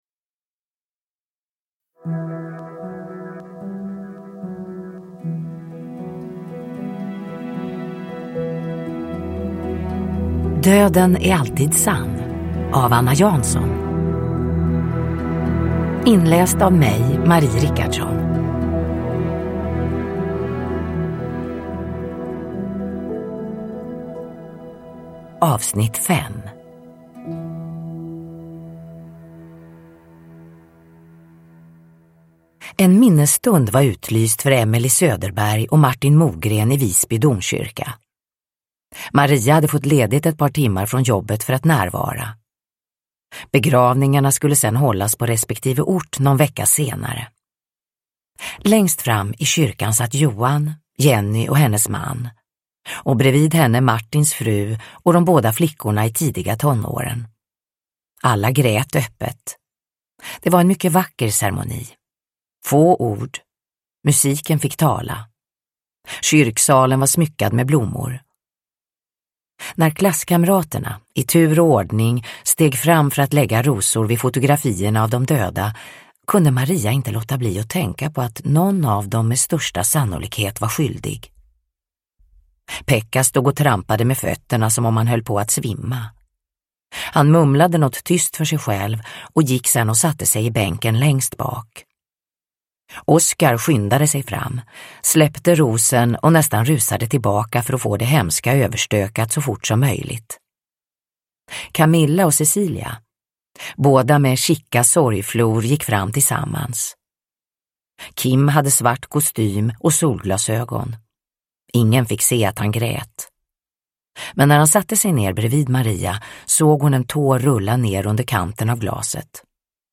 Döden är alltid sann - 5 – Ljudbok – Laddas ner
Uppläsare: Marie Richardson